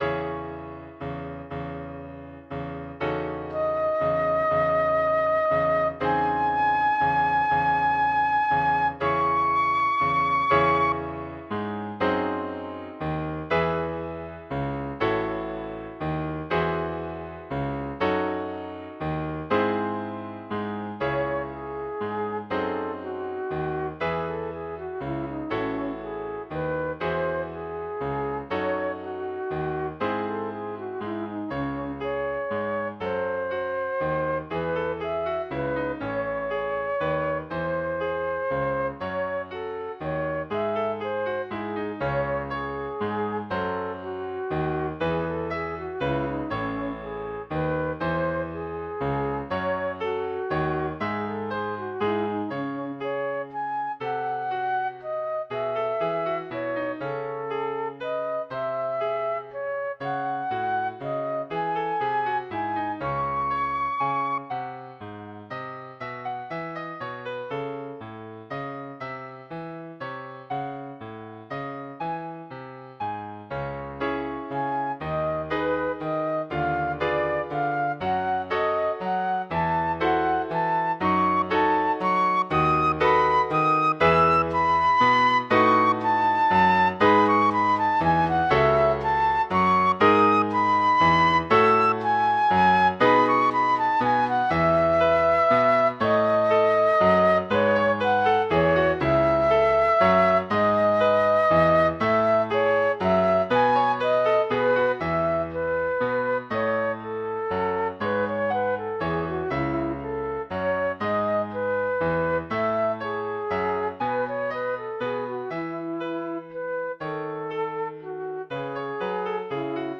I like counterpoint, and I like taking old forms and updating them.
This is a simple little thing for flute and piano.